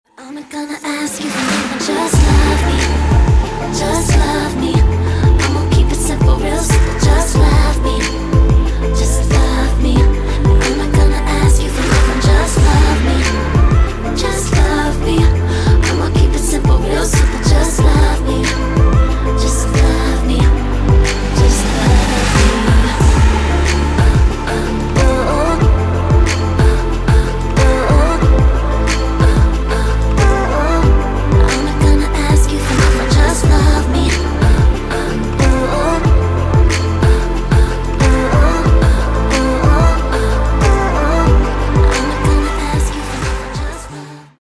• Качество: 192, Stereo
поп
женский вокал
dance
romantic